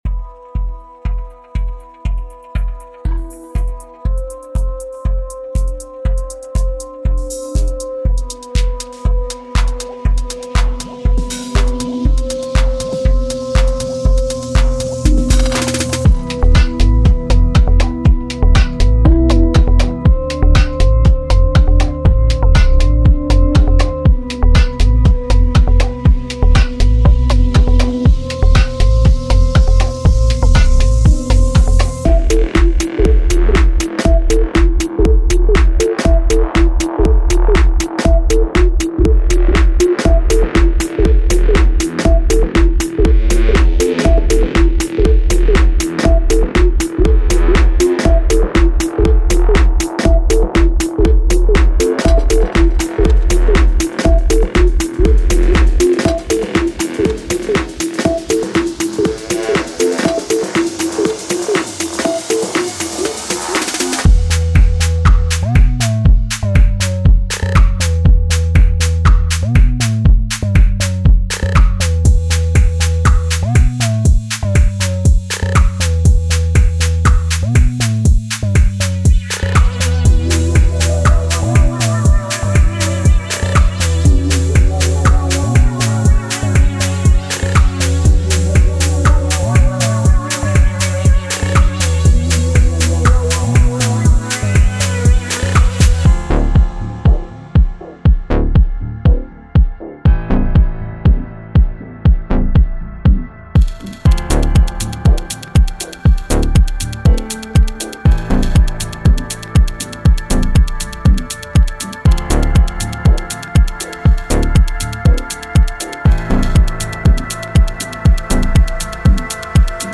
•201 One Shots (Claps and Snares, Hats, Kicks, Percs)
Demo